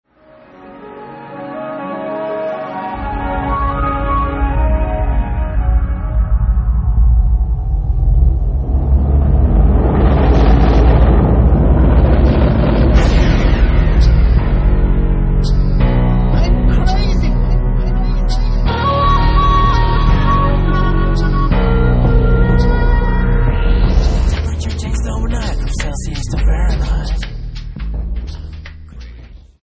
exotic over-blown flutes